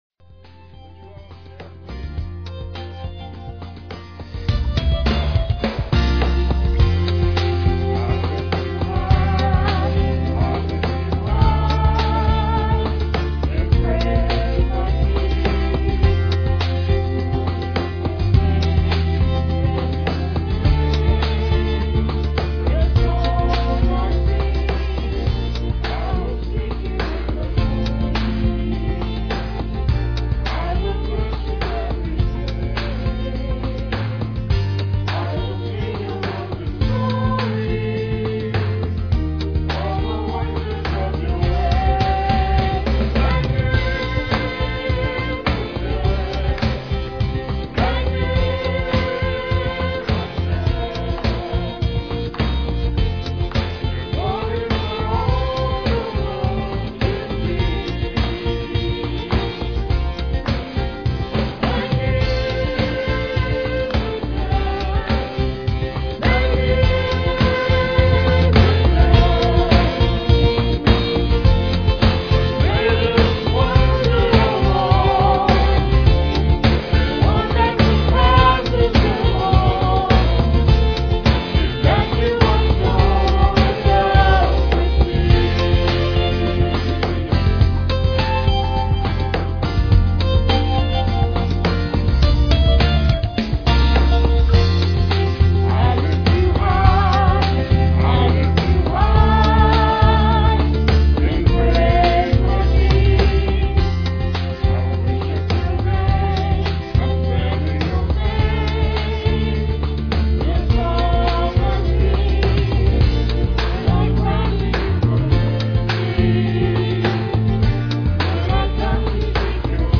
PLAY Man of Miracles, Part 3, Jul 23, 2006 Scripture: John 5:2-9. Scripture Reading
Solo